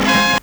HORN BURST-L.wav